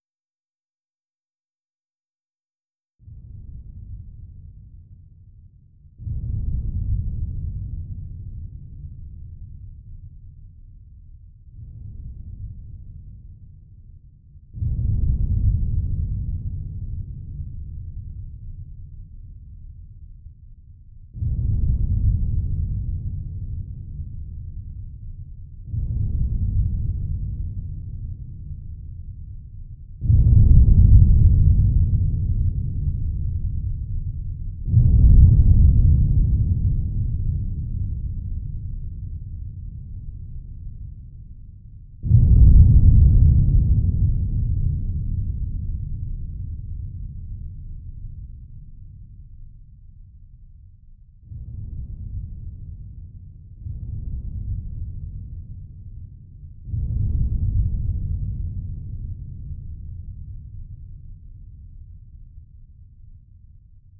distant-explosions.wav